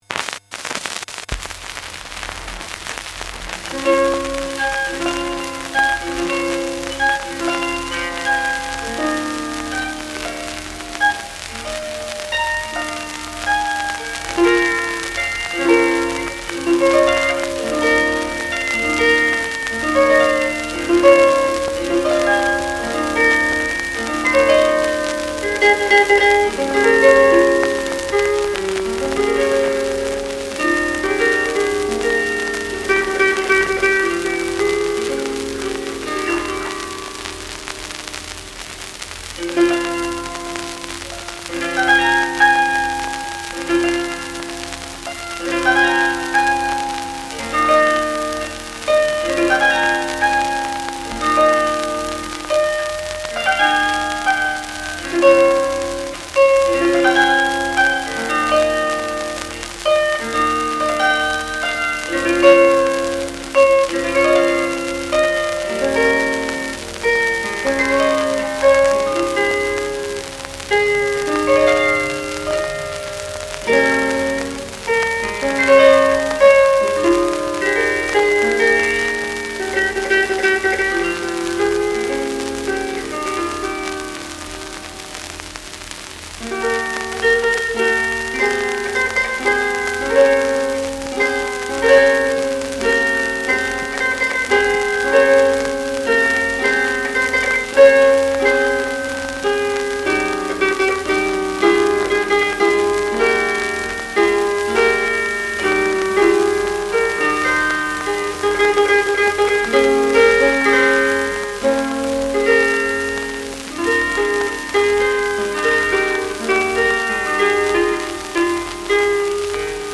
I have transferred them using a hifi turntable and lightweight pickup.
on the Bardic Harp